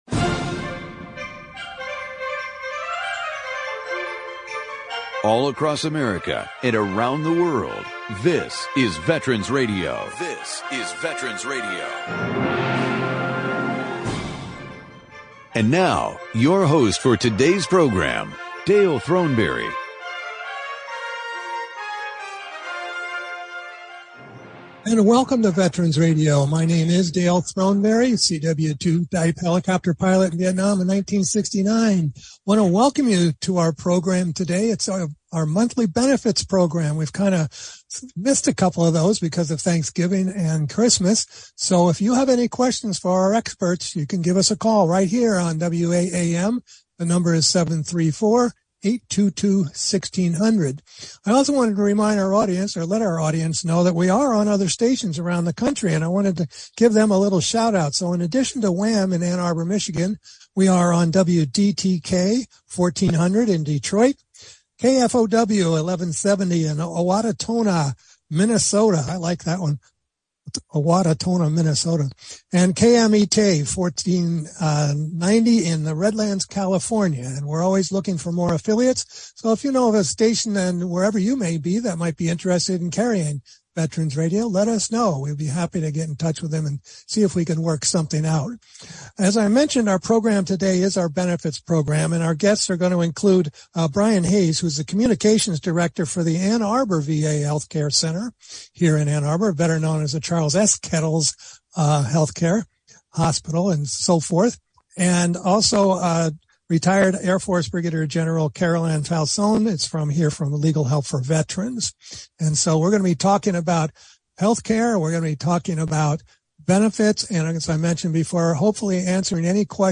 Call in during the program to ask your question or offer a comment.